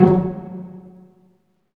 Index of /90_sSampleCDs/Roland LCDP13 String Sections/STR_Vcs Marc&Piz/STR_Vcs Pz.2 amb
STR PIZZ.M0C.wav